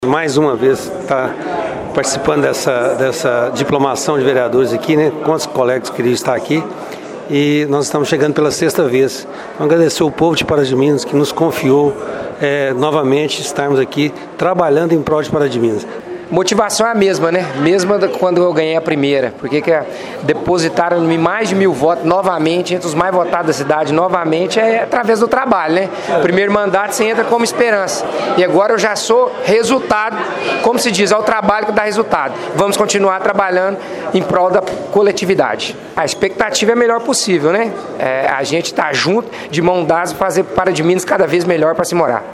Entre os diplomados, conversamos com os vereadores reeleitos de Pará de Minas, Marcílio Souza que foi diplomado pela 6ª vez, e Nilton Reis que recebeu seu 3º diploma. Eles falaram sobre esta experiência e a expectativa para mais um mandato.